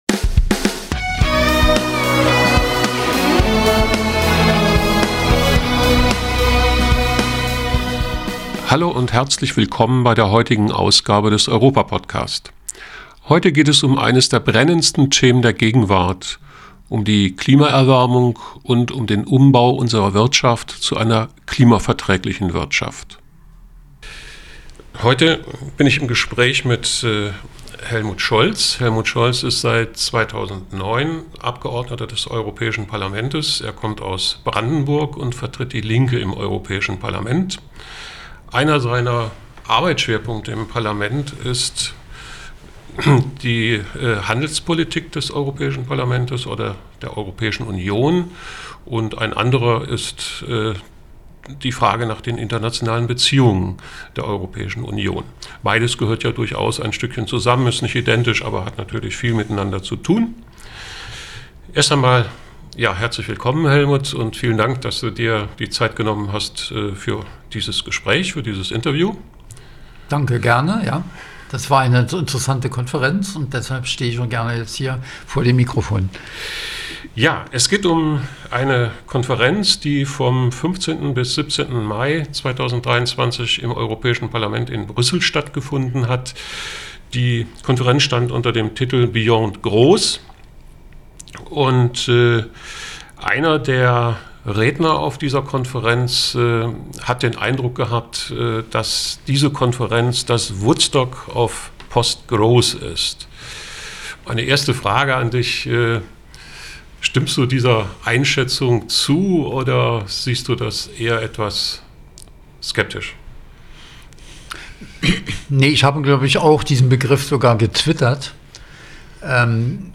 Beyond growth: Europa:Podcast im Gespräch mit MdEP Helmut Scholz über die EP-Konferenz über klimaverträgliches Wirtschaften